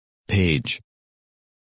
Написание и аудио произношение – Spelling and Audio Pronunciation